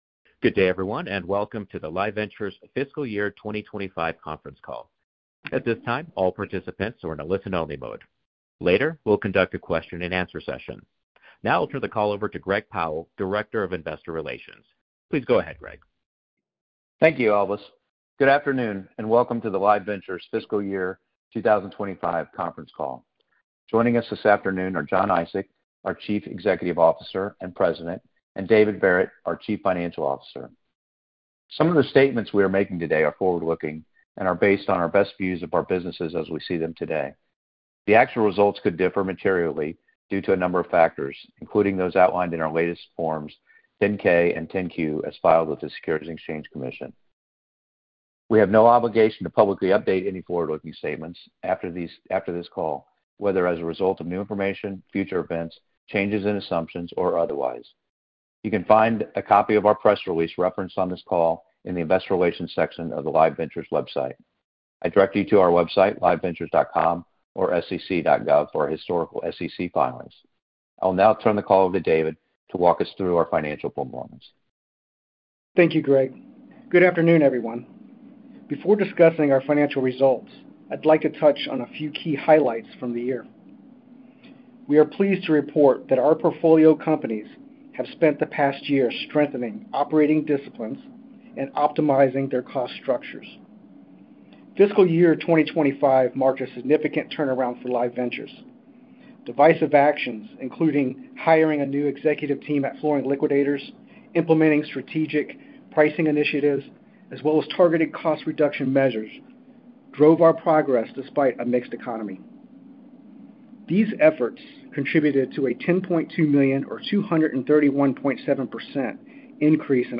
Second Quarter 2025 Financial Results Conference Call